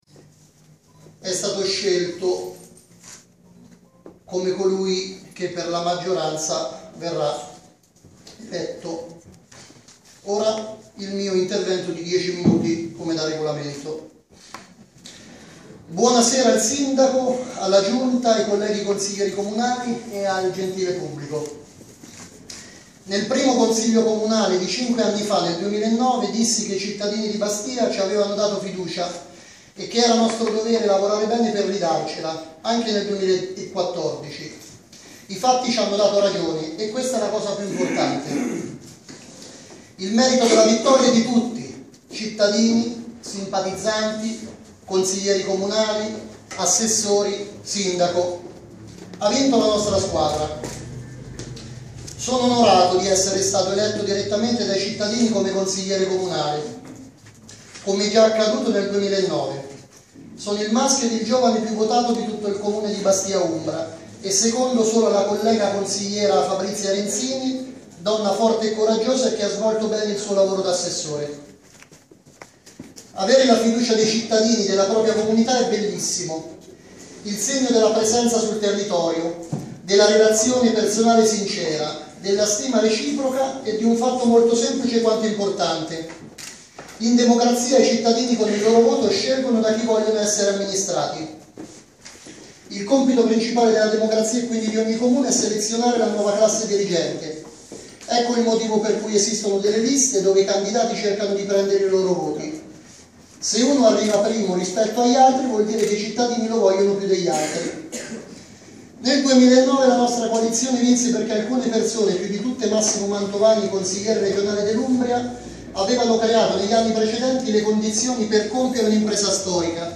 Insediamento Sindaco, il discorso di Jacopo Cairoli, consigliere giovane più votato